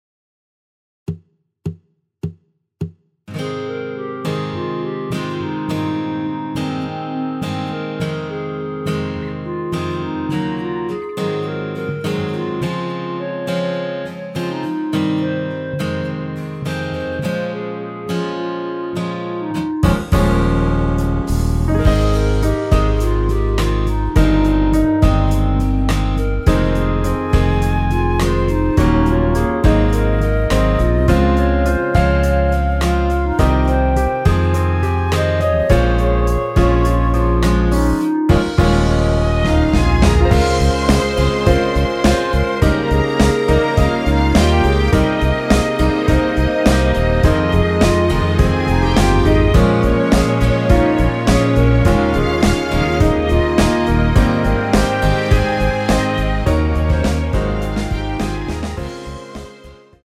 원키에서(-2)내린 멜로디 포함된 MR입니다.
Ab
앞부분30초, 뒷부분30초씩 편집해서 올려 드리고 있습니다.